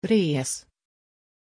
Aussprache von Reese
pronunciation-reese-sv.mp3